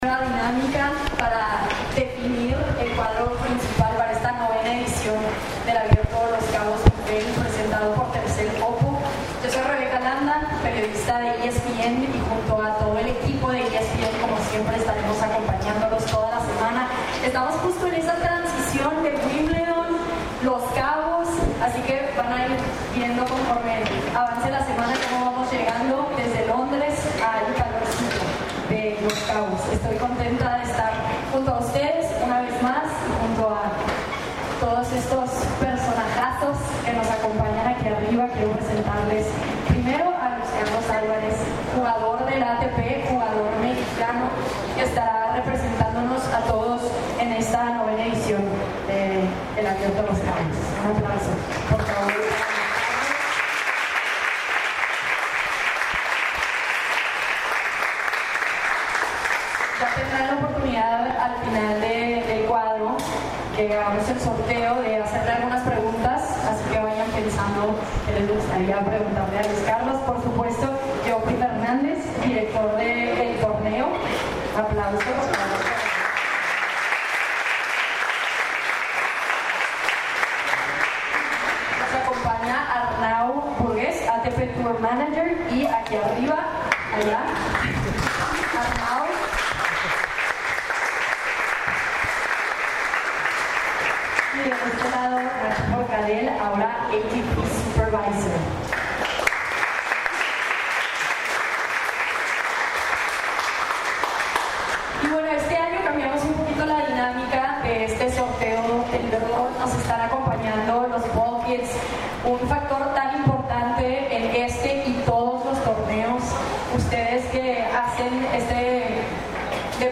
Audio_ceremonia_draw_LCTO2025.MP3